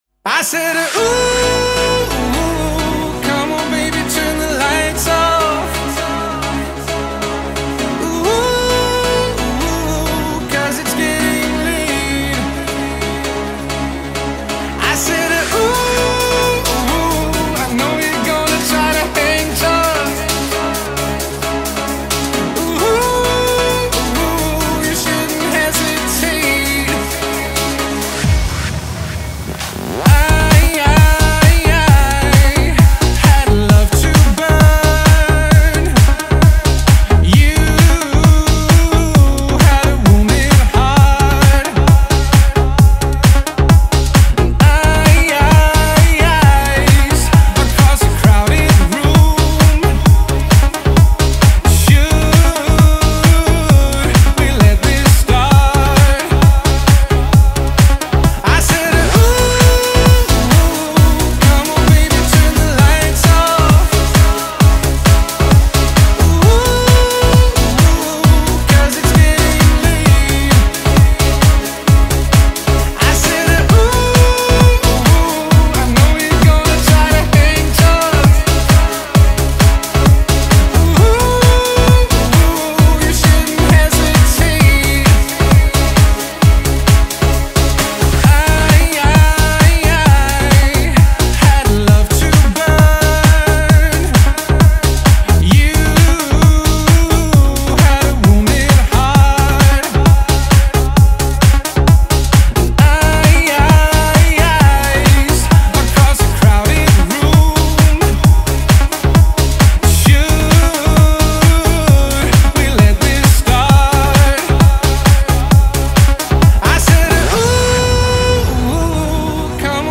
BPM132
Audio QualityPerfect (Low Quality)